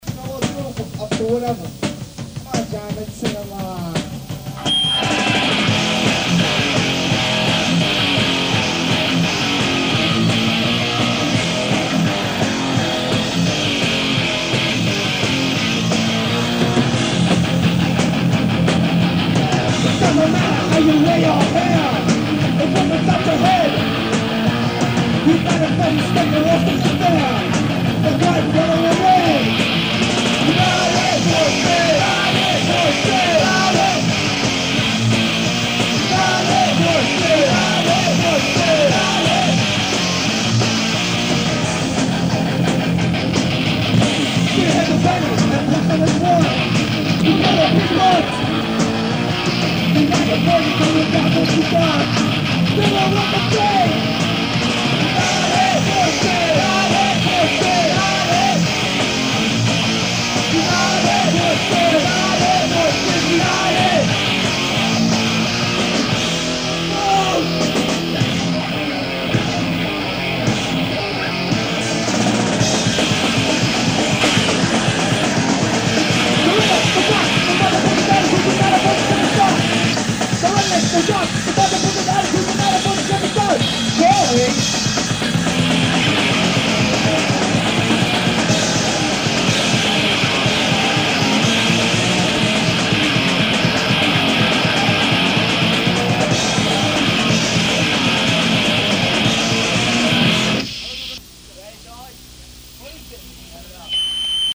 Quality: ***½